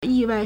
意外 yìwài
yi4wai4.mp3